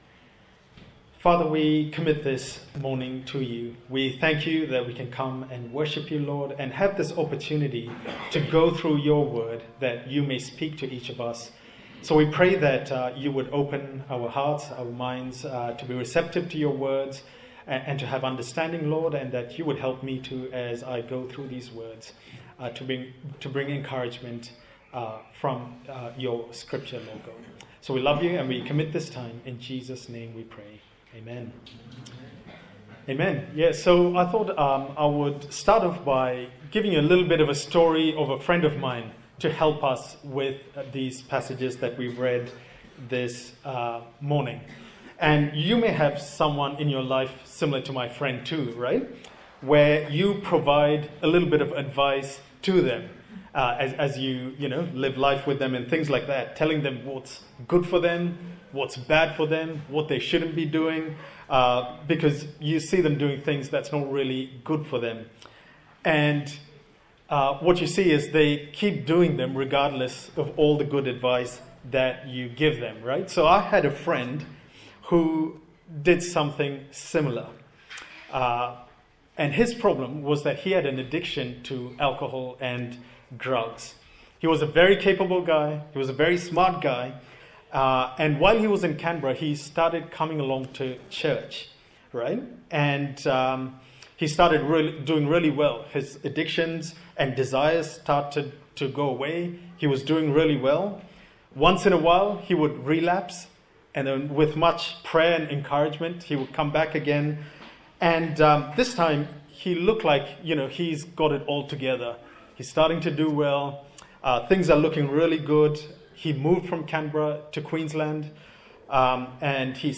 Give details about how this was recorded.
Galatians Passage: Galatiants 4:8-31 Service Type: Sunday Morning